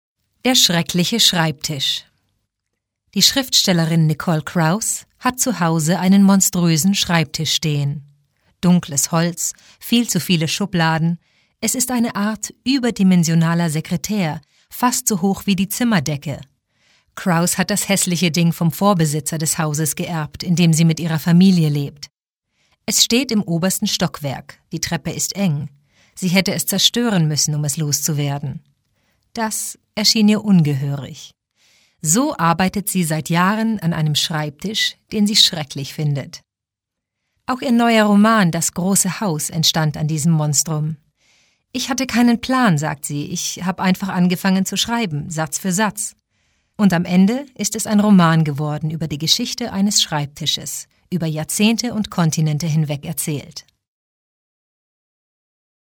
middle west
Sprechprobe: eLearning (Muttersprache):
Native speaker in English (U.S.) and German